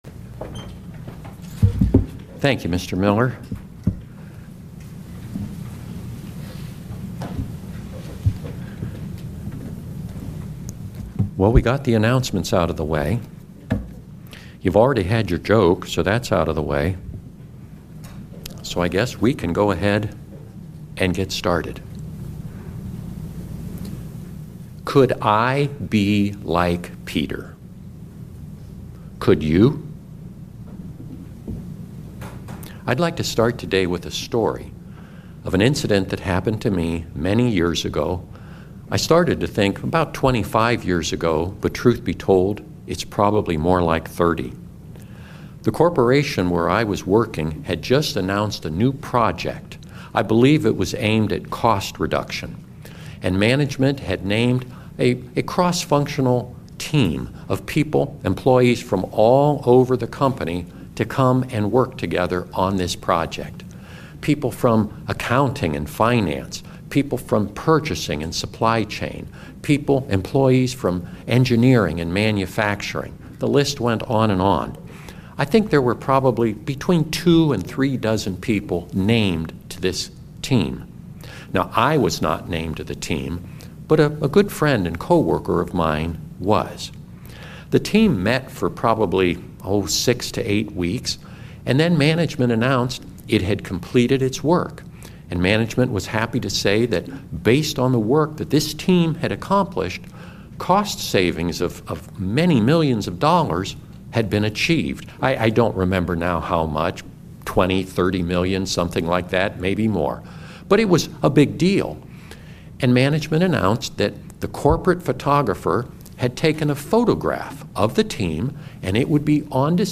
Given in North Canton, OH